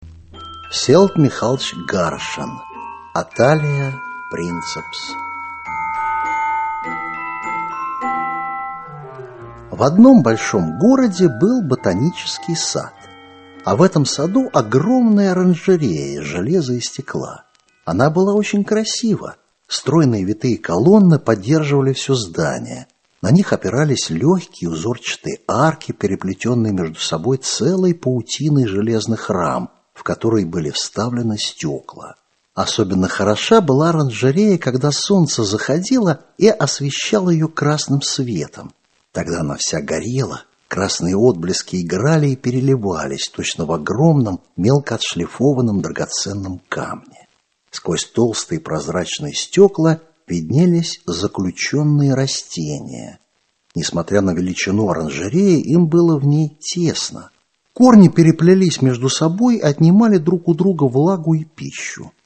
Aудиокнига Лягушка путешественница Автор Всеволод Гаршин Читает аудиокнигу Валерий Золотухин.